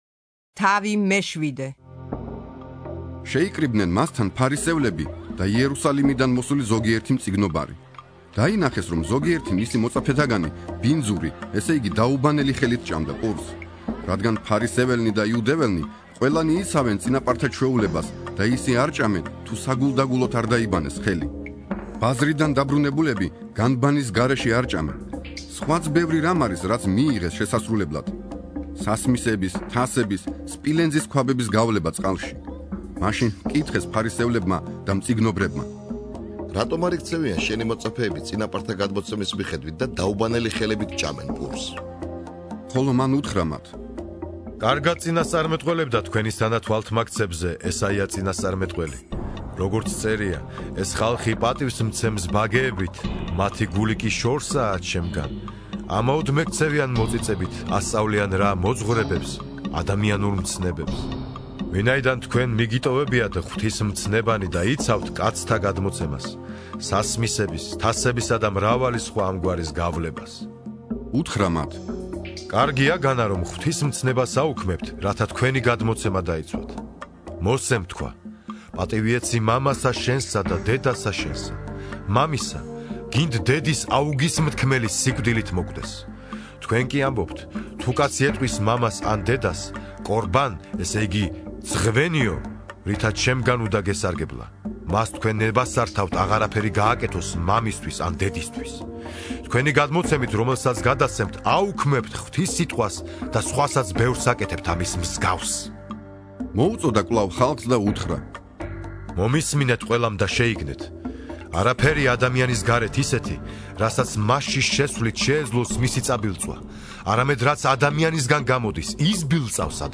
(41) ინსცენირებული ახალი აღთქმა - მარკოზის სახარება